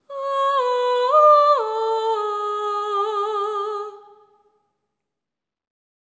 ETHEREAL10-R.wav